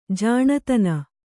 ♪ jāṇatana